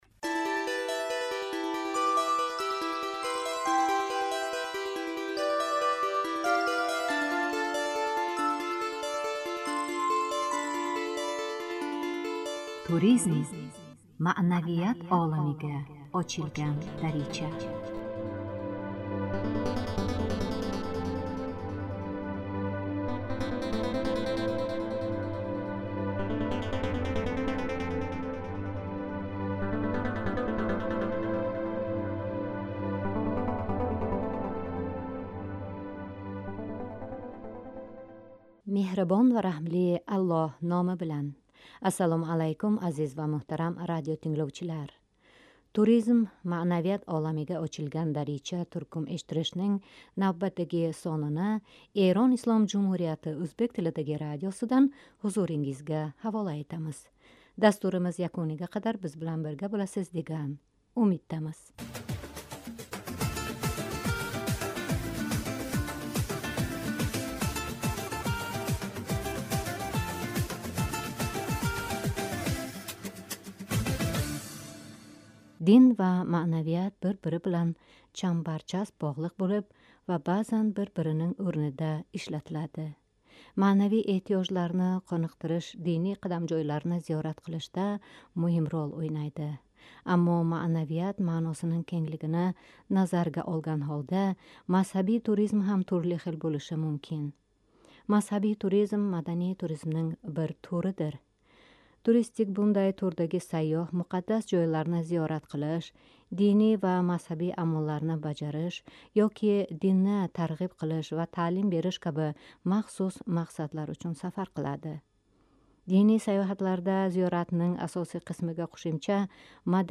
“ Туризм-маънавият оламига очилган дарича” туркум эшиттиришининг навбатдаги сонини Эрон Ислом Жумҳурияти ӯзбек тилидаги радиосидан ҳузурингизга ҳавола этамиз.